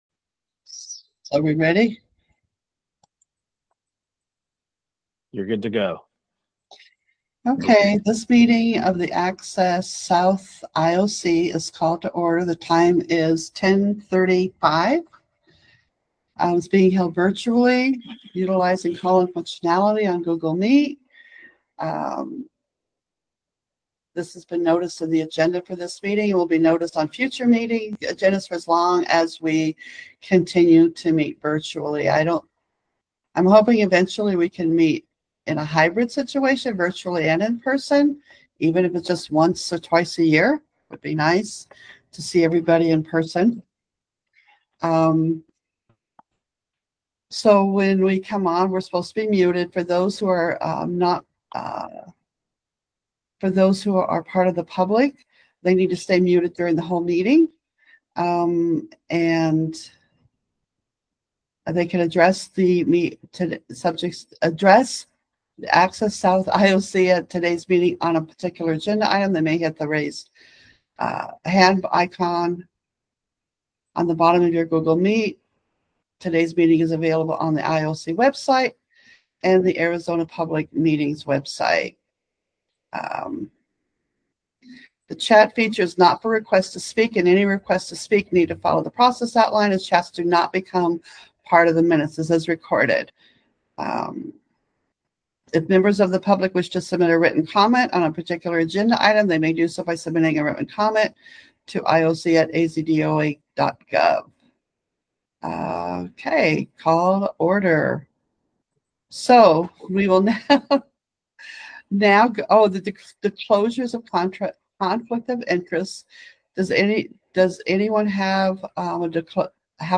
Virtual Meeting Only